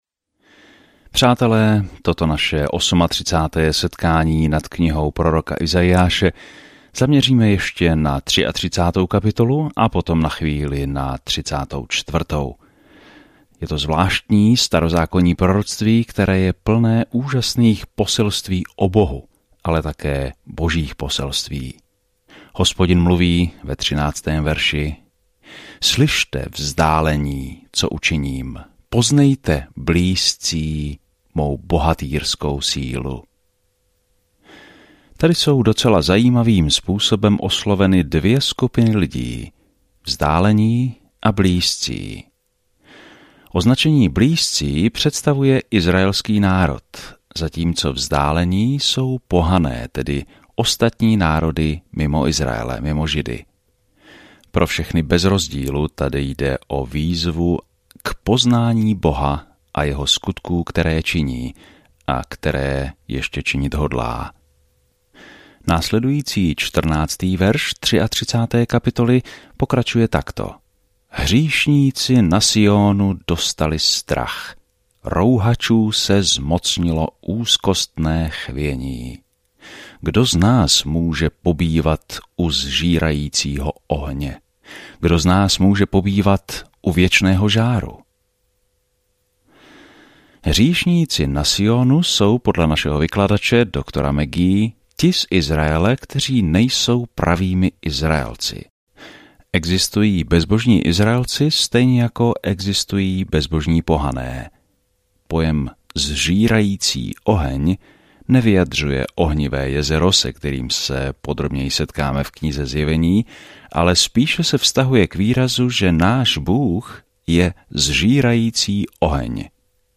Denně procházejte Izajášem a poslouchejte audiostudii a čtěte vybrané verše z Božího slova.